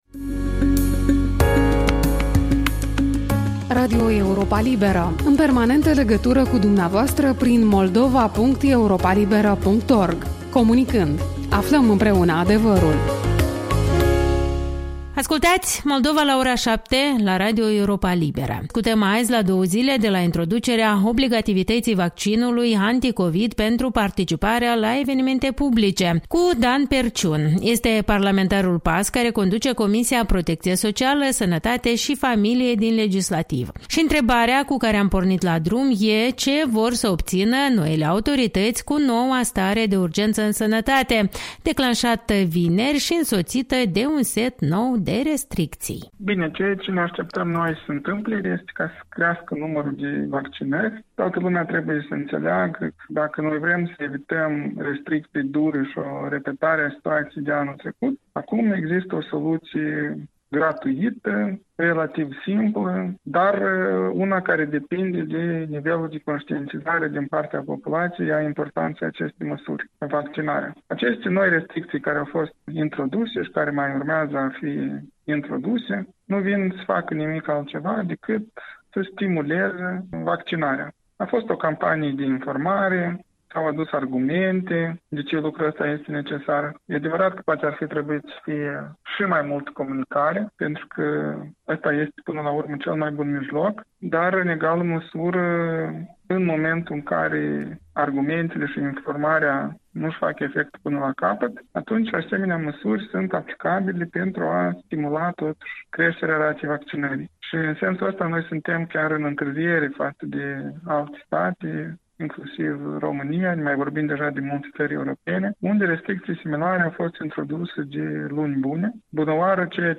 Interviu cu Dan Perciun, deputat PAS despre ultimele restricții sanitare